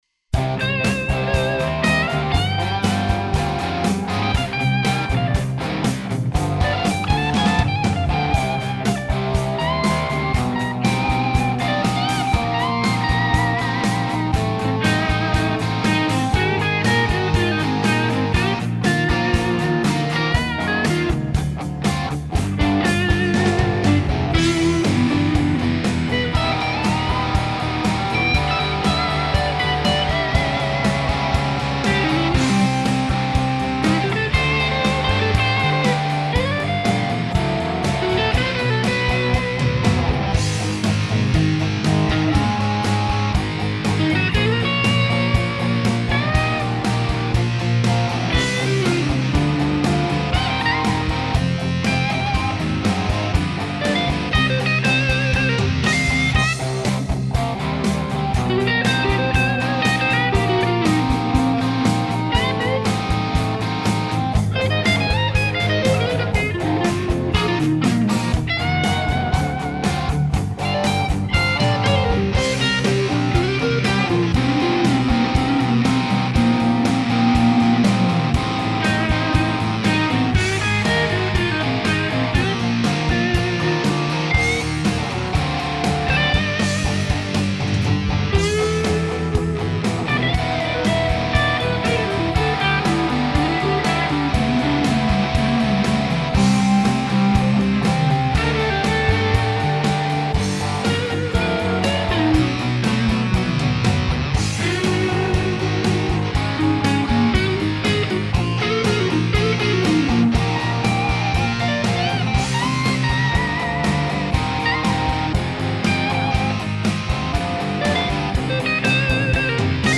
今回も、「リアルトラック」というサンプル音源だけで曲作りに挑戦してみました。
アメリカン・ハードロックに挑戦！
ボーカルが無いので、ギター三本ぐらい無いと淋しいのかも知れません。
やはり、ボーカル抜きのハードロックって、何か物足りない気もするのですが、試行錯誤の末こんな曲になりました。